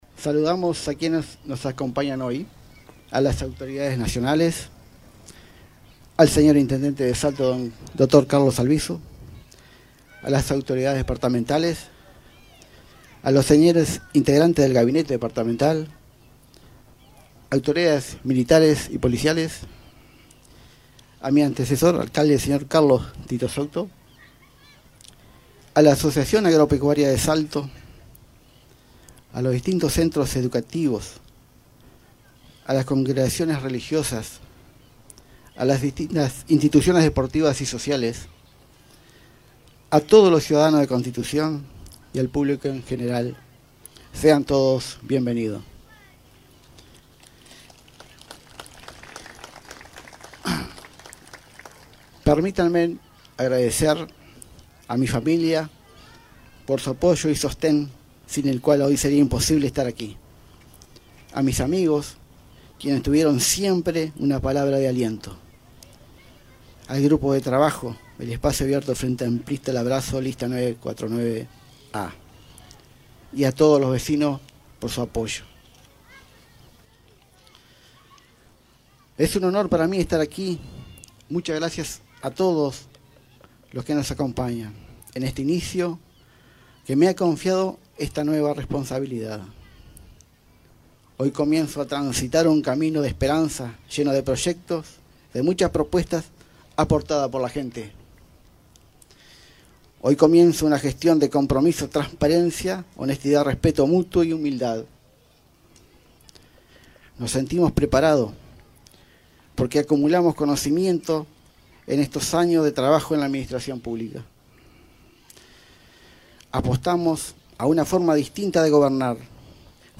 Luego, el flamante alcalde Luis Enrique Valerio dirigió un discurso cargado de agradecimientos y compromiso. Saludó a las autoridades presentes y agradeció especialmente a su familia, amigos y a los vecinos por el respaldo.